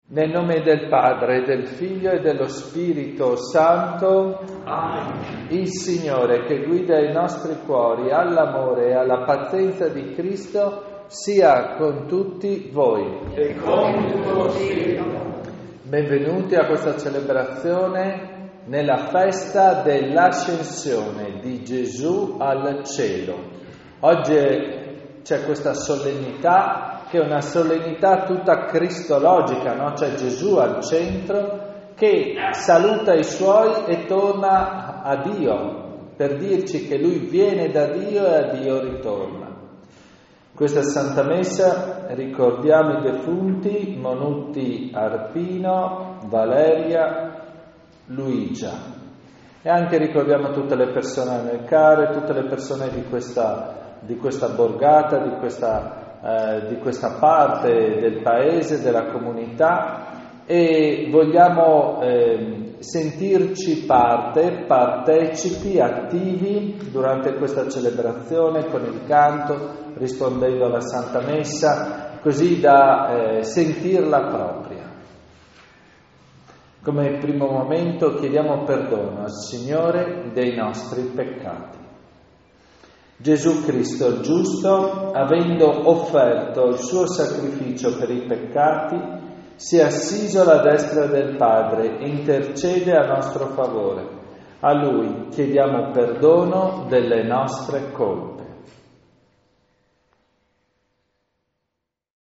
Leproso di Premariacco (UD), 12 Maggio 2024
SCAMPANATA
Messa Solenne nel giorno dell'Ascensione e Sagra di Leproso